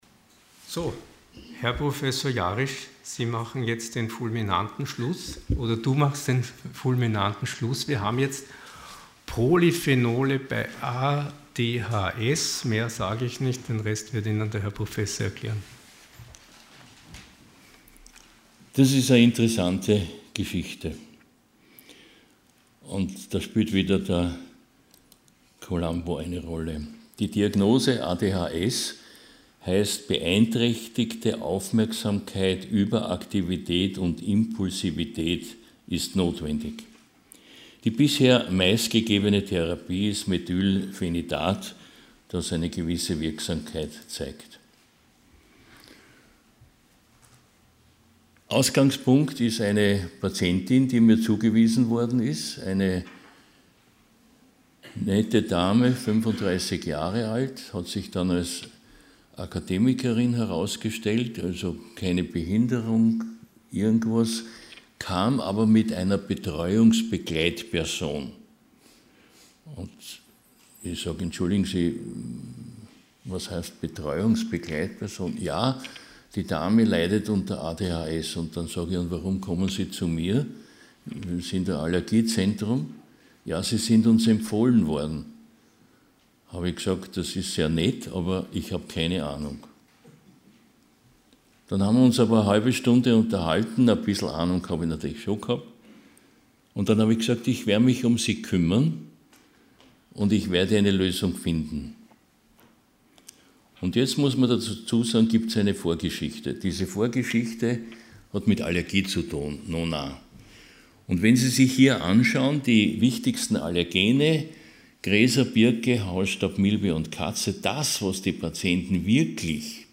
Sie haben den Vortrag noch nicht angesehen oder den Test negativ beendet.
Ort: Fach: Allgemeinmedizin Art: Fortbildungsveranstaltung Thema: - Veranstaltung: Hybridveranstaltung | Spermidin und Melatonin bei Demenz, Long COVID und COPD Moderation: